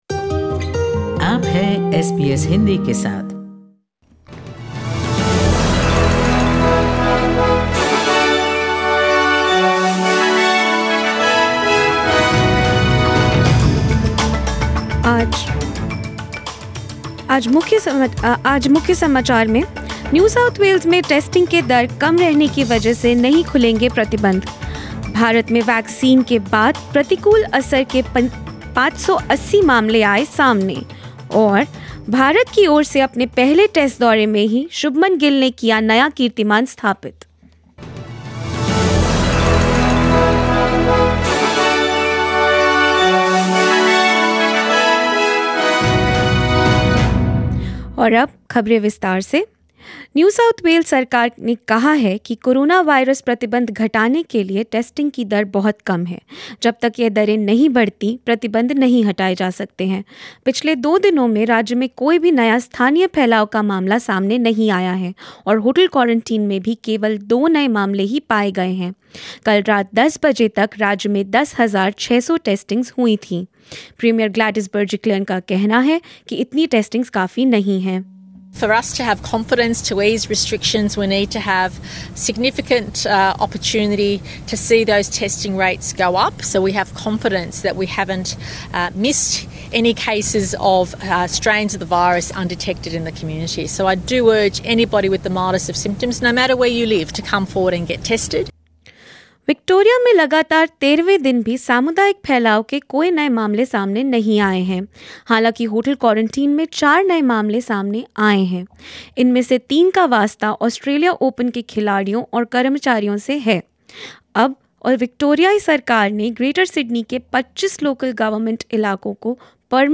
News in Hindi: 580 AEFI cases in India after 2 days of immunization drive, and more